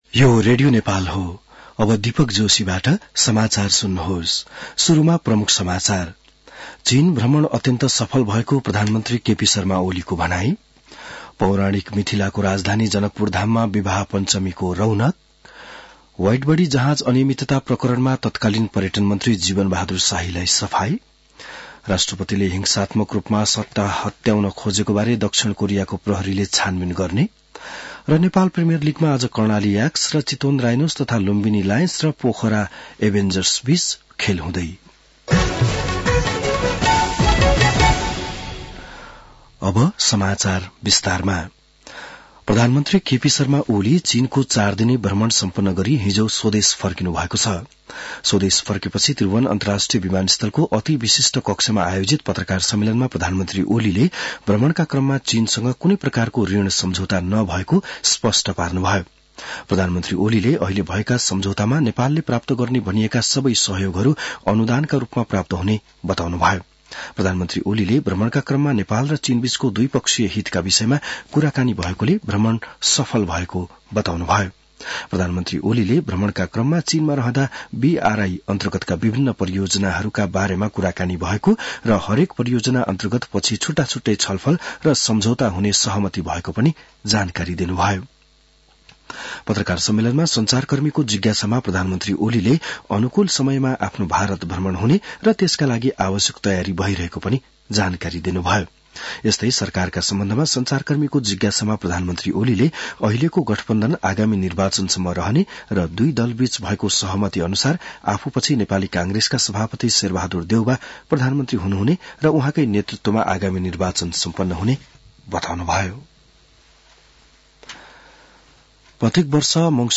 बिहान ९ बजेको नेपाली समाचार : २२ मंसिर , २०८१